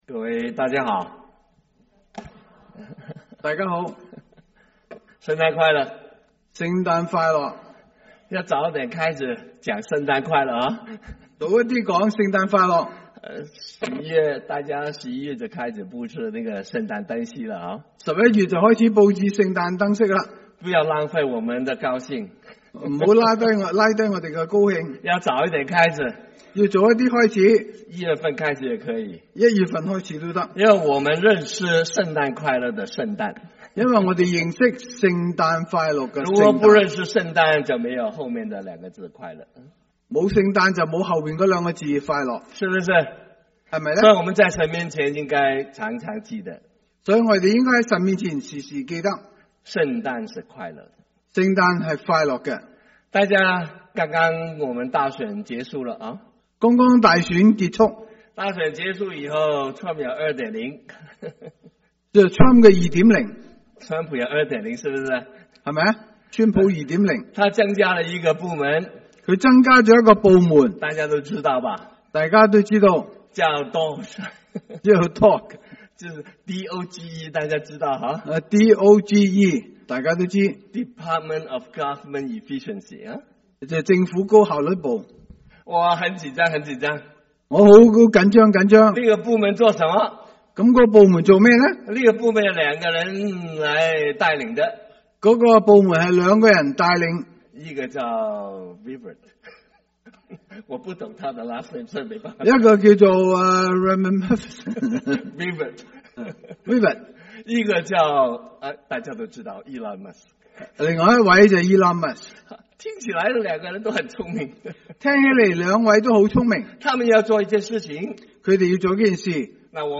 12/15/2024 國粵語聯合崇拜: 「救贖萬民的「審核報告」」